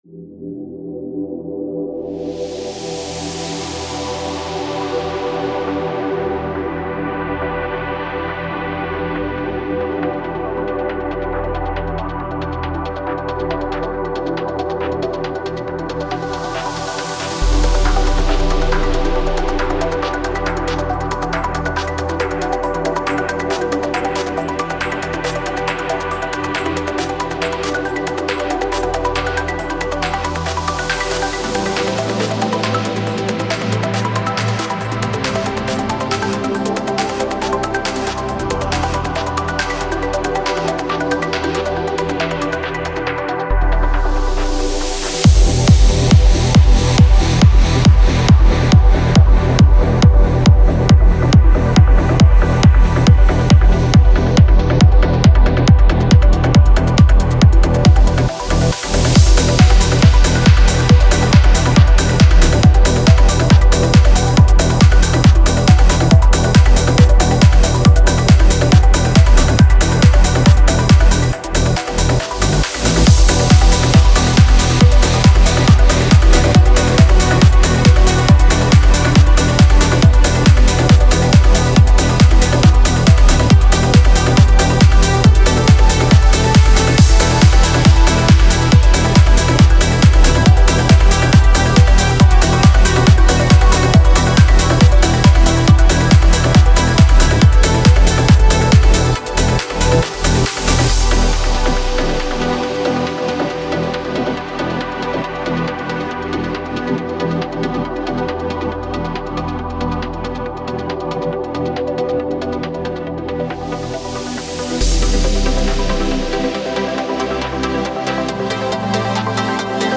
Ощущаю как 4/4. Это потому что там 16ми нотами.